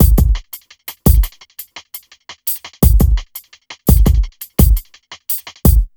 1TI85BEAT5-L.wav